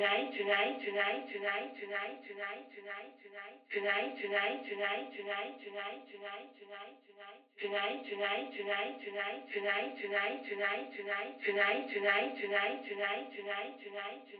voc tonight.wav